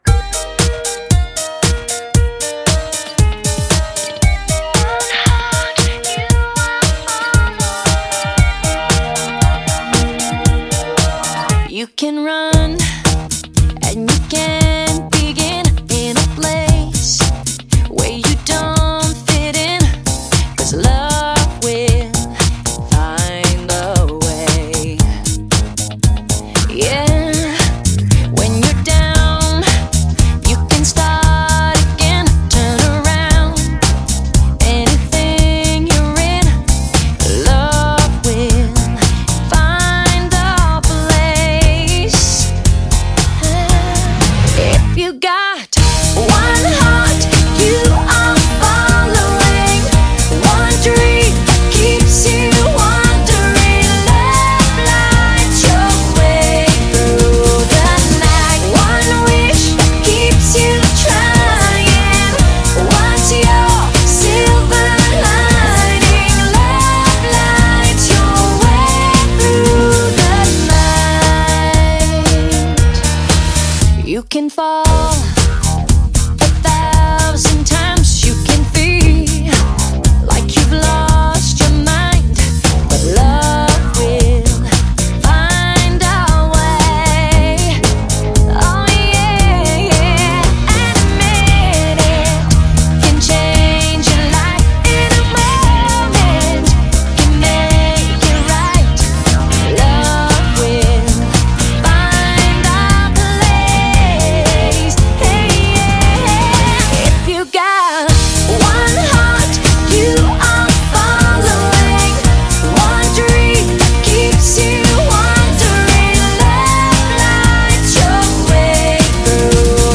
轻快愉悦的风格